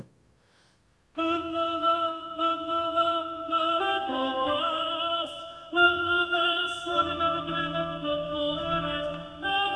As seen from the second example, the model can replicate human singing and humming.
Jingle Bells with marimba and opera singer
jingle-bells-opera-singer.wav